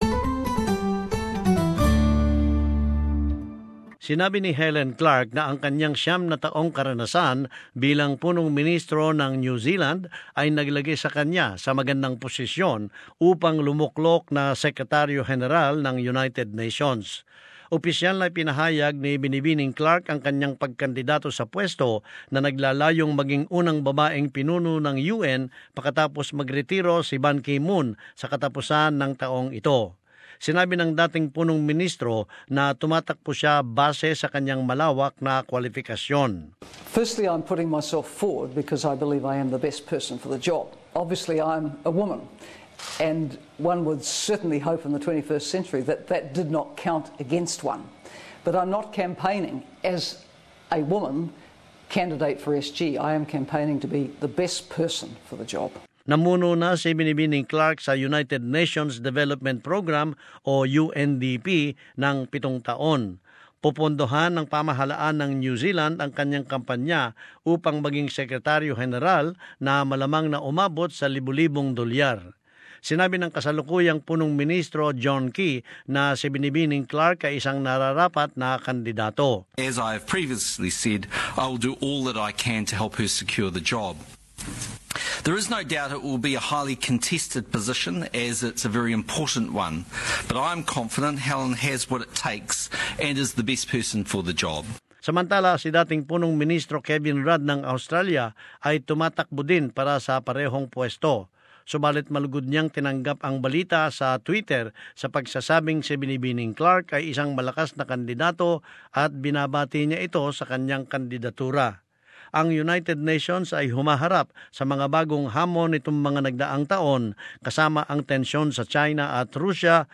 As this report shows, if successful, Clark would be the first woman to hold the top post.